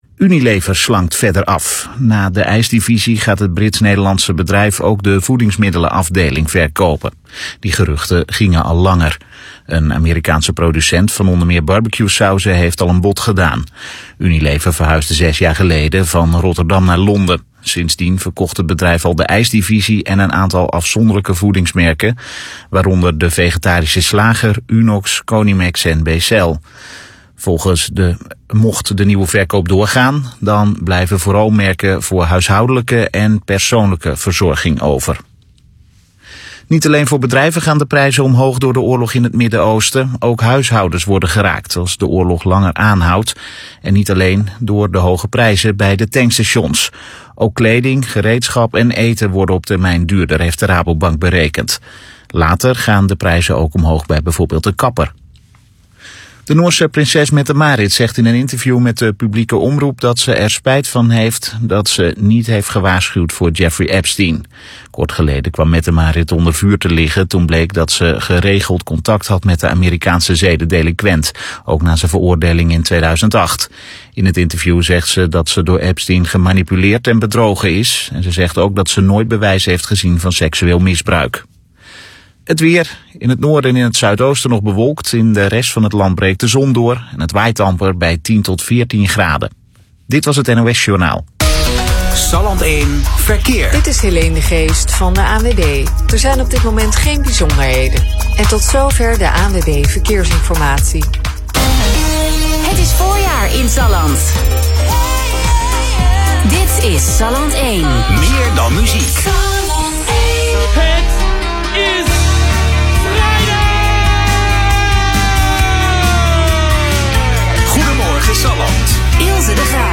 Interview-DVBD-Salland-Radio.mp3